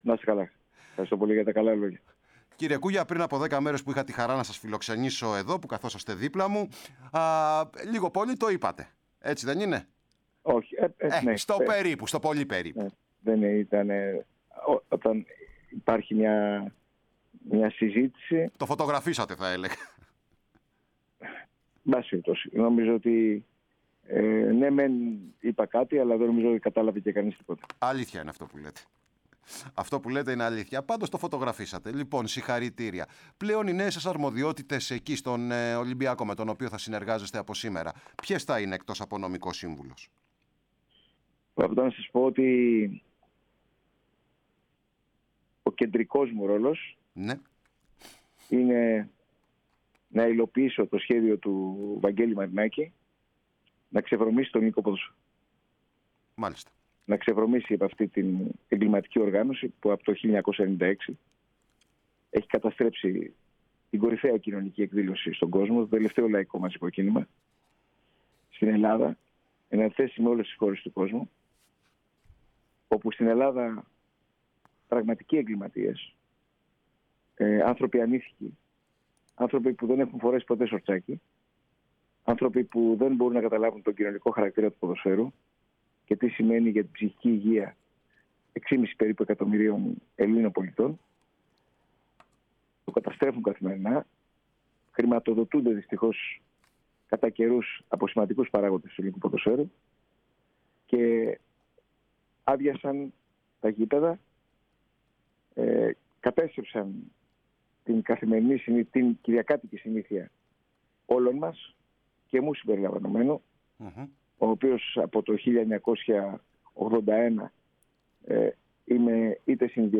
Ο Αλέξης Κούγιας ανακοινώθηκε από την ΠΑΕ Ολυμπιακός ως ο νέος σύμβουλος αλλά και εκπρόσωπος της ομάδας  στο συμβούλιο της Super League με την ιδιότητα του Αντιπροέδρου.